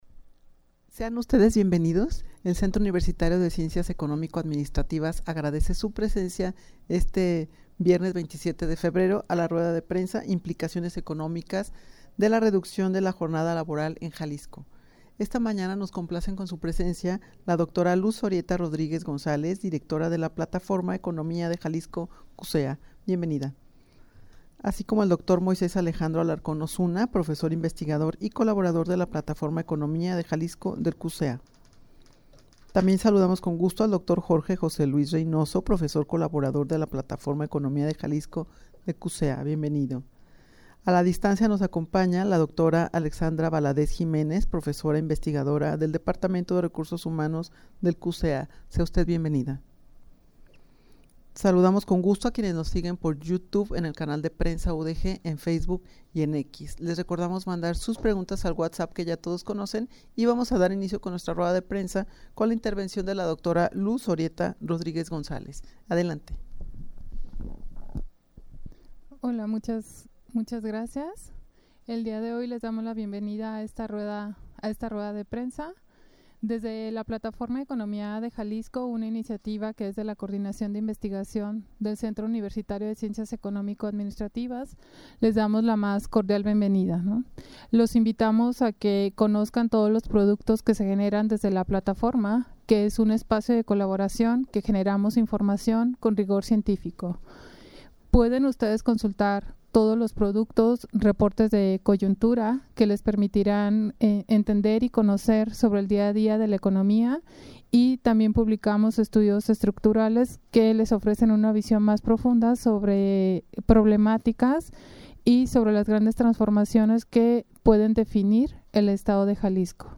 Audio de la Rueda de Prensa
rueda-de-prensa-implicaciones-de-la-reduccion-de-la-jornada-laboral-en-jalisco.mp3